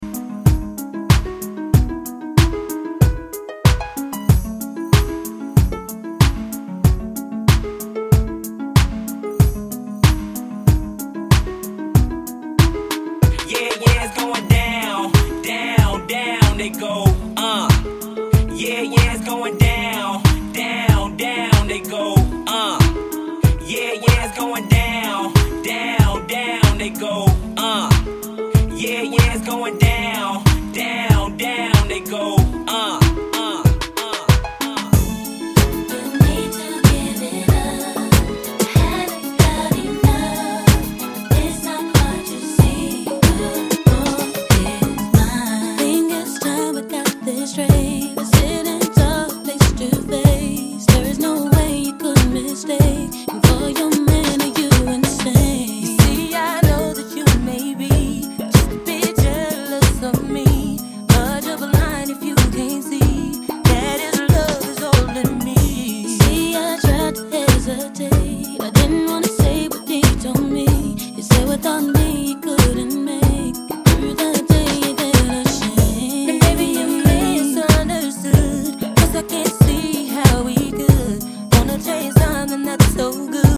sean Genre: TOP40 Version: Clean BPM: 93 Time